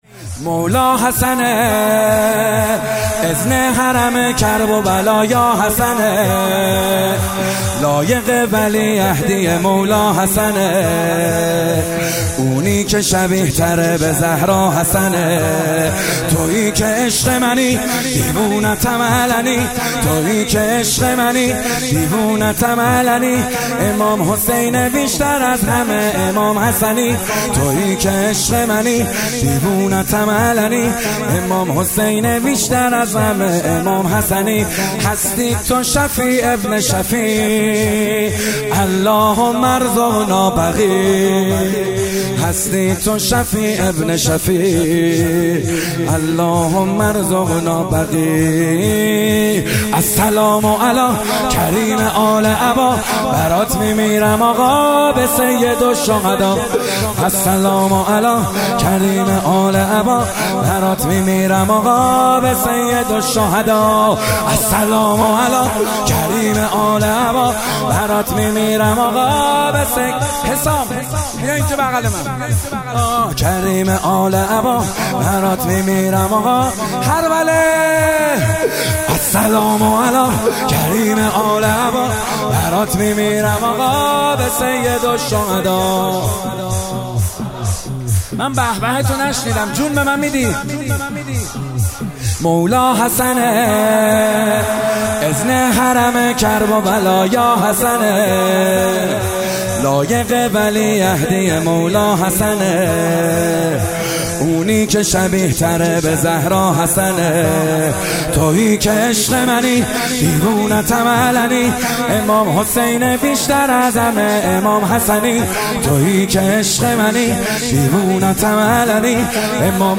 محرم98 - شب پنجم - شور - مولا حسنه
متن نوحه
مهدیه امام حسن مجتبی(ع)